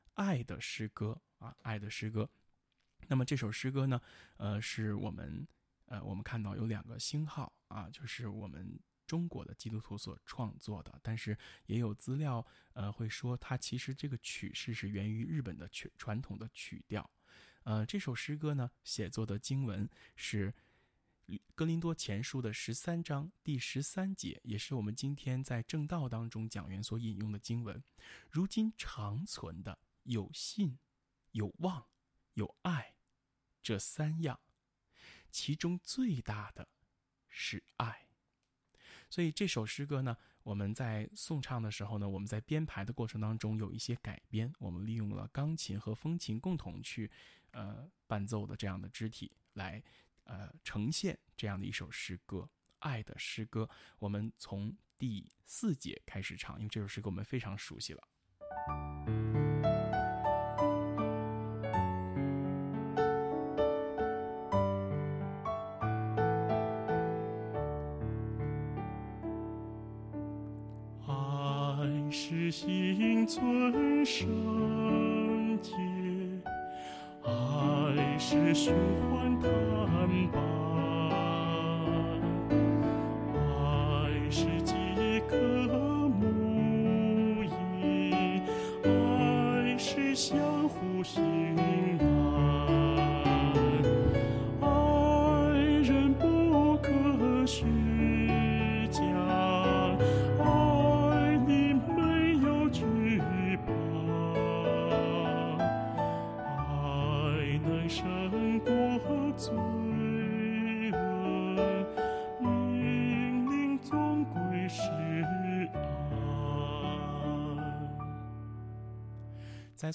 【颂唱练习】